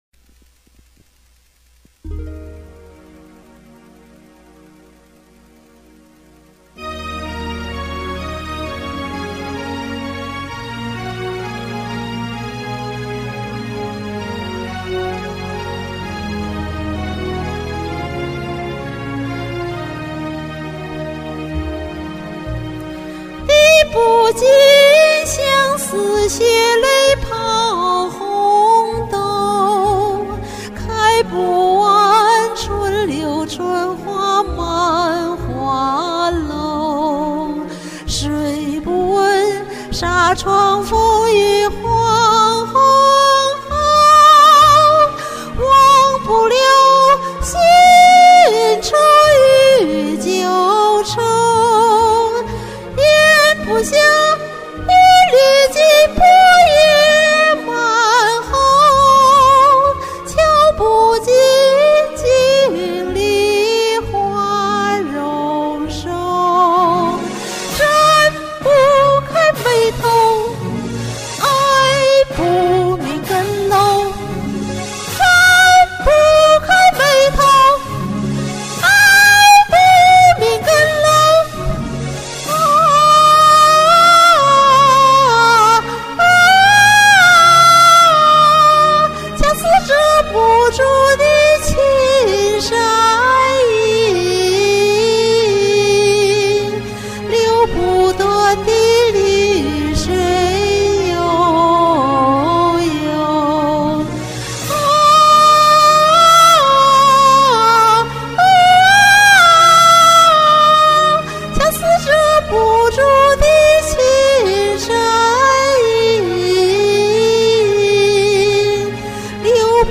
一次高音練習
回來後不久，我就錄了一遍這首歌，但從來沒貼到山上，因為我發現：這歌唱得既過載，又噴麥。
雖然是一氣呵成唱下來的，唱到最後也沒提氣，但是，讓它還是在冷宮裡呆着去吧！
明亮的嗓音，唱得有張力，看來發小指揮得當，贊！
這麼高的音，一氣呵成唱下來的！